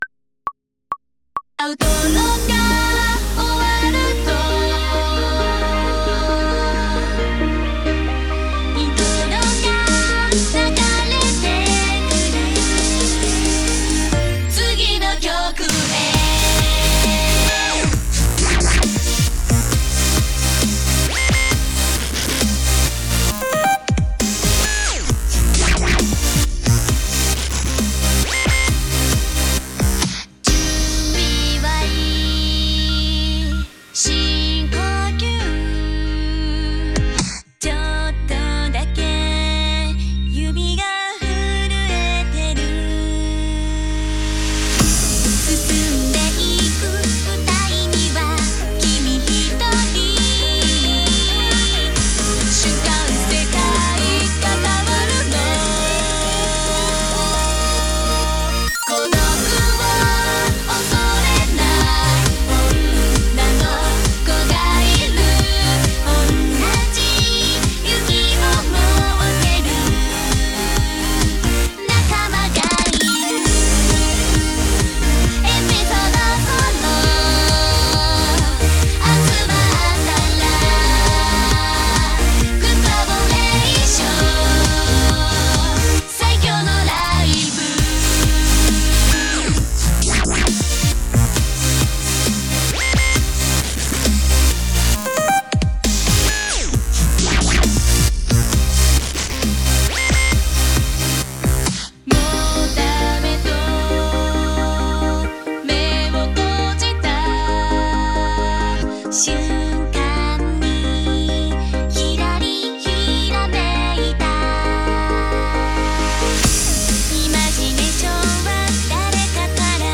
bass/drums/vocals are spleetered, all the synths are re-done
(and the FX added etc)